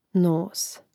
nȏs nos